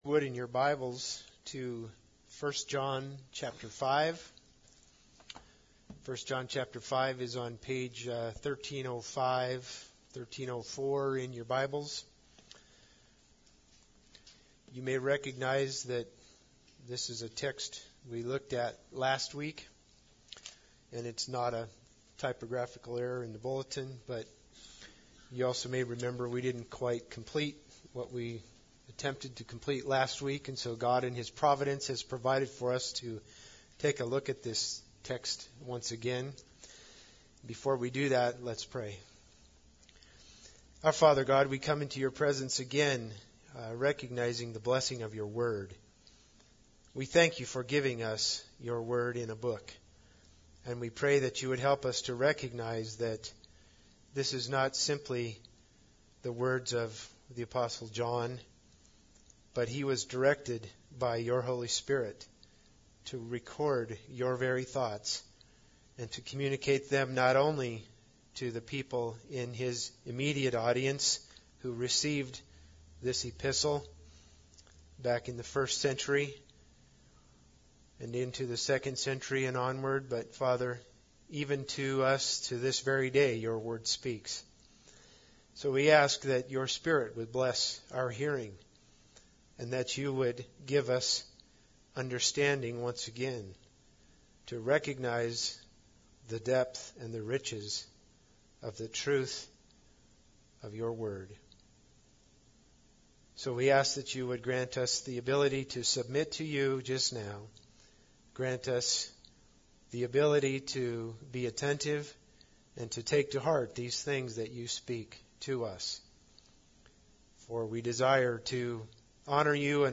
1 John 5:18-21 Service Type: Sunday Service Bible Text